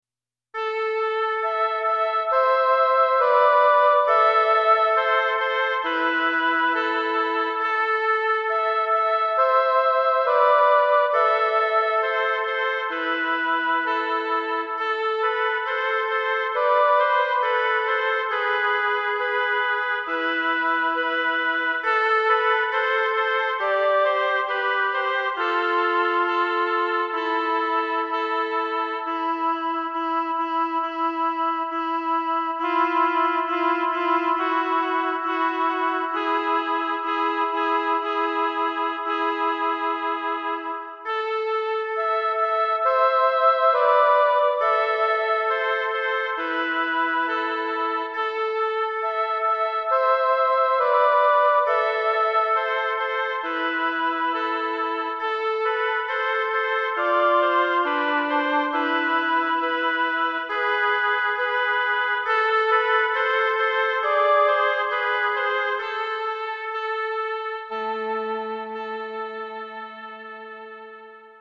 オーボエ／イングリッシュホルン